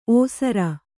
♪ ōsara